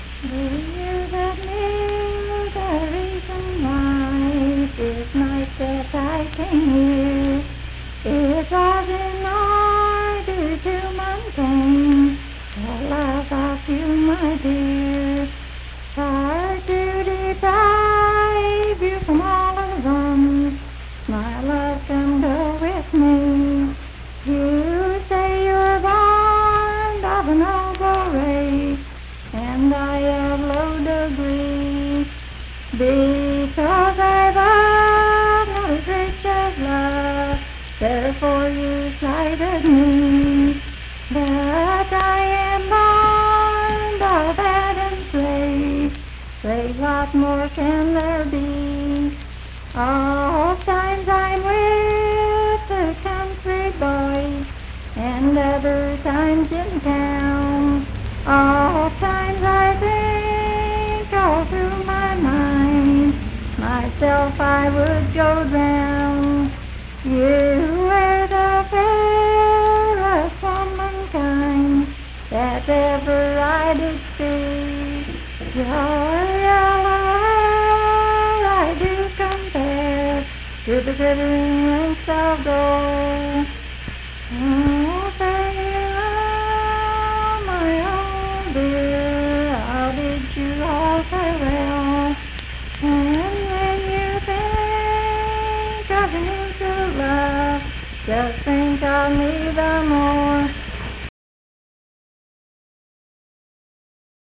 Unaccompanied vocal music
Verse-refrain 6(4).
Voice (sung)
Spencer (W. Va.), Roane County (W. Va.)